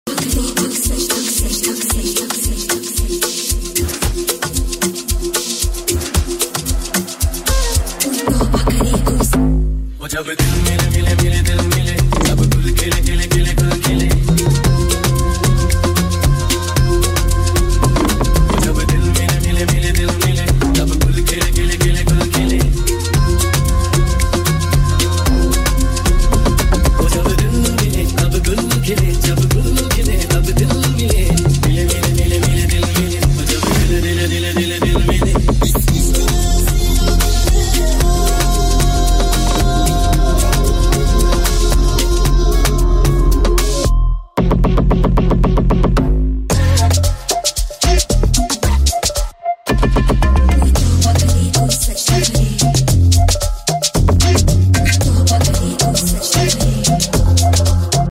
Fusion Mix